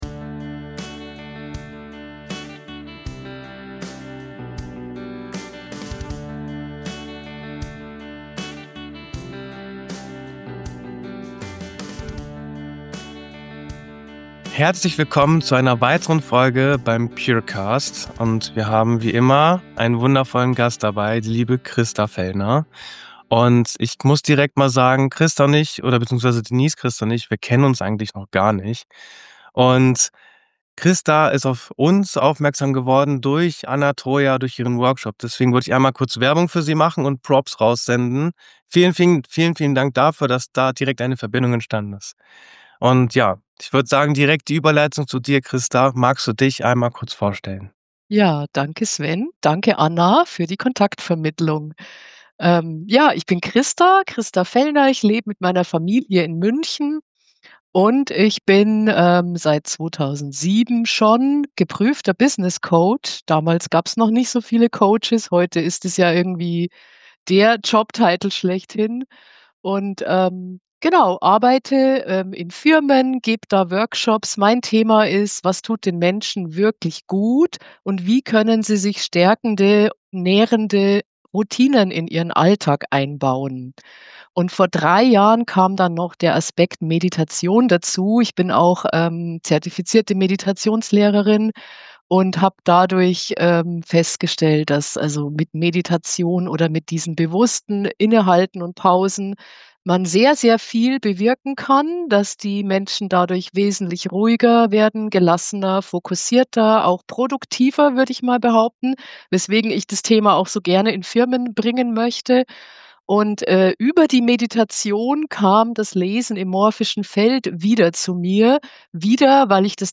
Diese Folge ist ein Gespräch über das Menschsein.